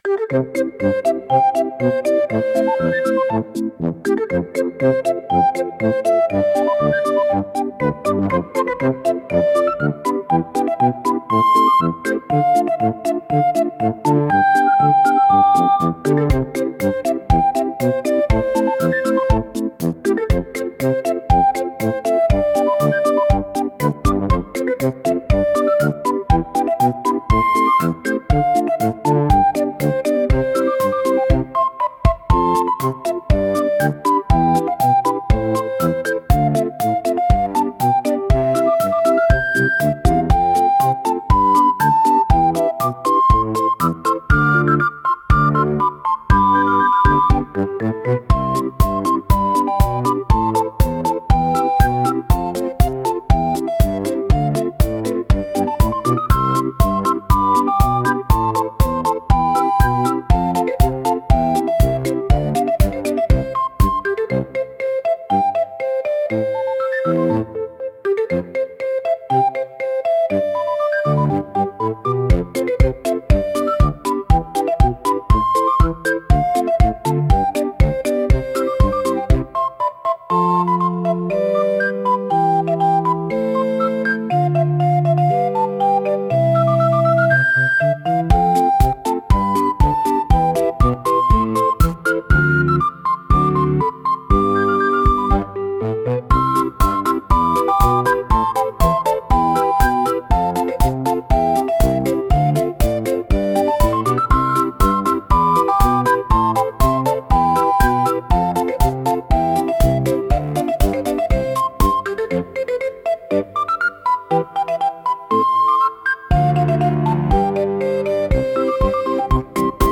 ほのぼの , ムービー , リコーダー , 日常 , 春 , 昼 , 穏やか , 笛 , 静か